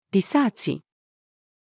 リサーチ-female.mp3